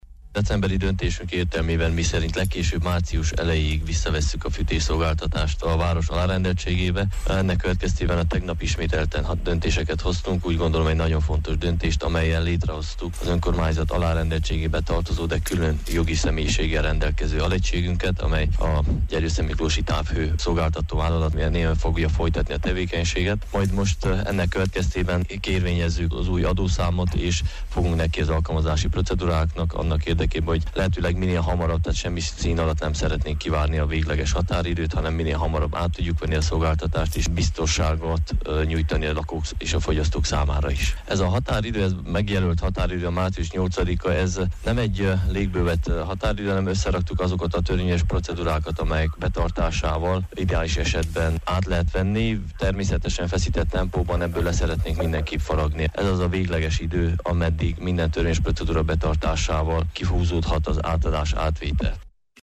Nagy Zoltán gyergyószentmiklósi polgármester úgy nyilatkozott: tegnapi döntésük nyomán március elejére várhatóan létrejön az önkormányzat alárendeltségébe tartozó távhőszolgáltató vállalat, ami végleg megoldaná a város fűtésgondját.